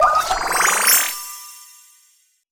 spell_recharge_poweup_02.wav